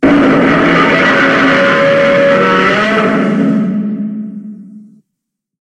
Звуки Годзиллы
Здесь вы найдете его мощный рев, грохот шагов, звуки разрушений и другие эффекты из фильмов и комиксов.
Звук ревущего Годзиллы